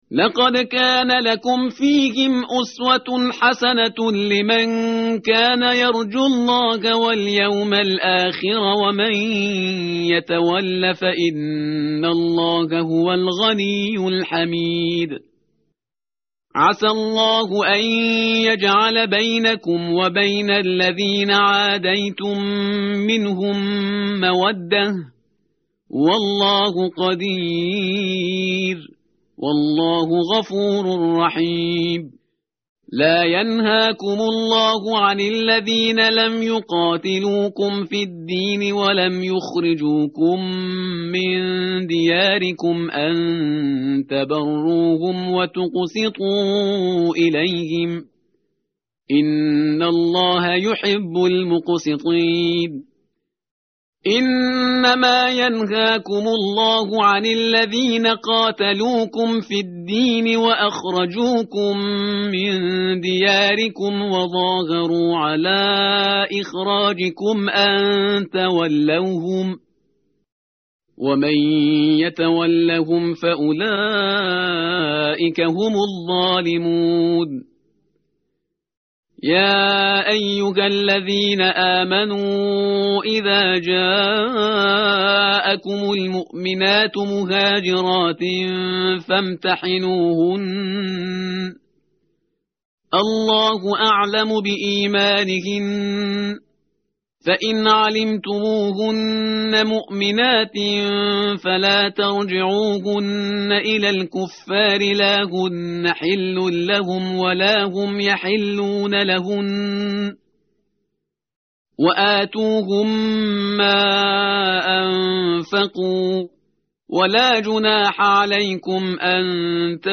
متن قرآن همراه باتلاوت قرآن و ترجمه
tartil_parhizgar_page_550.mp3